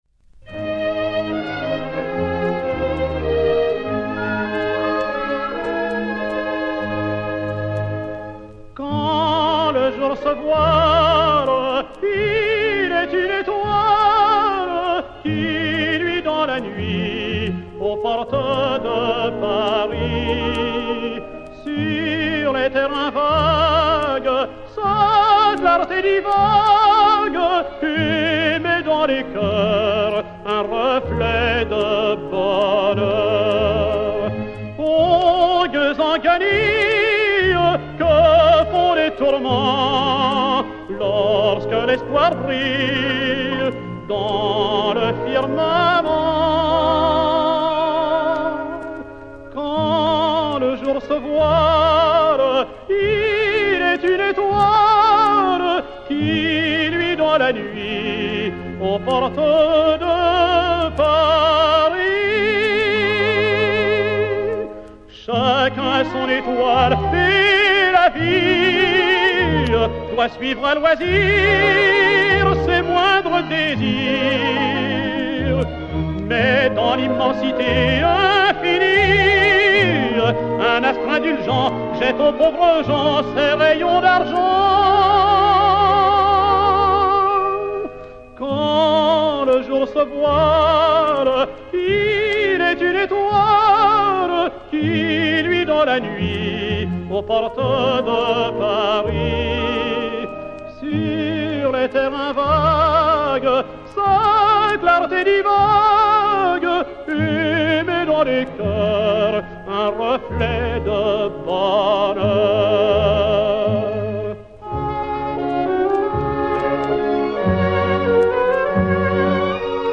Valse chantée
ténor de l'Opéra